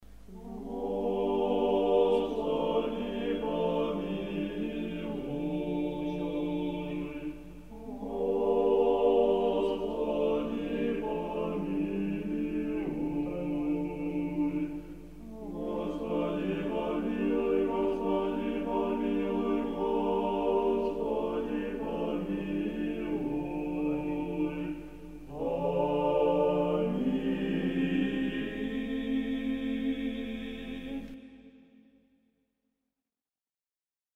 угубая постовая ектения Валаамского монастыря